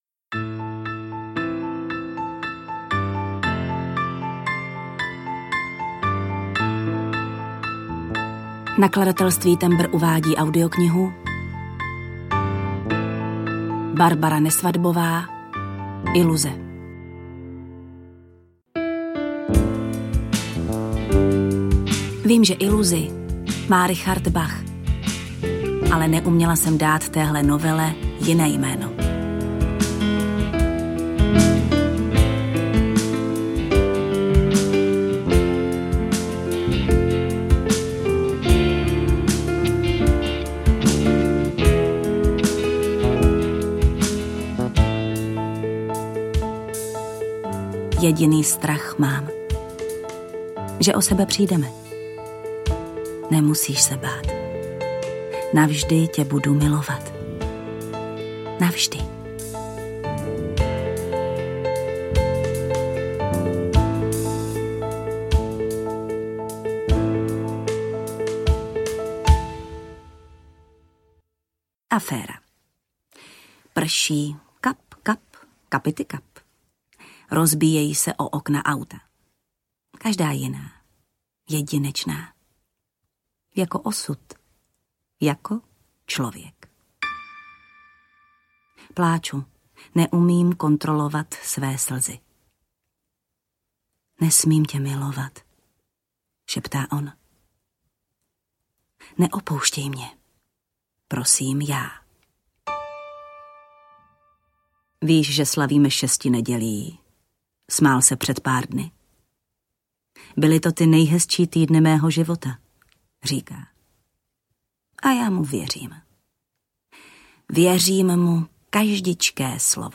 Iluze audiokniha
Ukázka z knihy
• InterpretJana Stryková